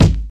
Kick
Smooth Kick Sample G# Key 70.wav
mid-heavy-steel-kick-drum-g-sharp-key-09-sCF.wav